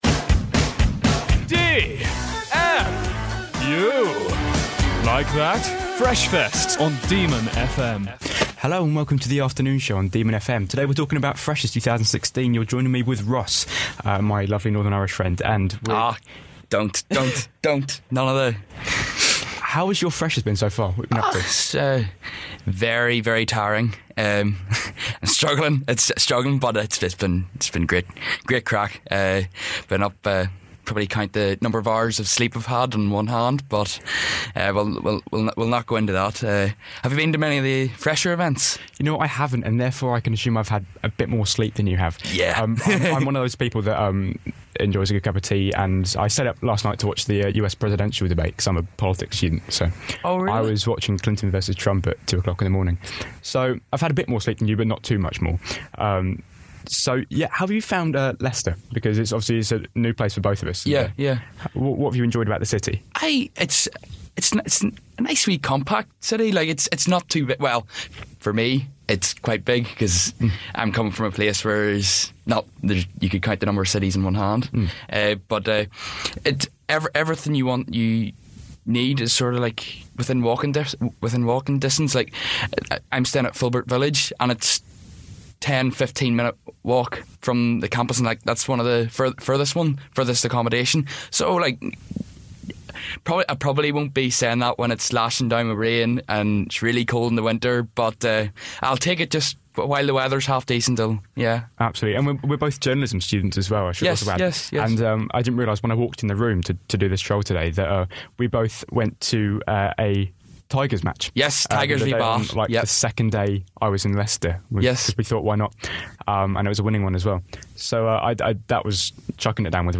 As part of Demon Media’s Open Day, DemonFM gave freshers and students at De Montfort University the chance to make their own mock radio shows to see what joining DemonFM could be like.